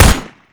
rifleShoot.ogg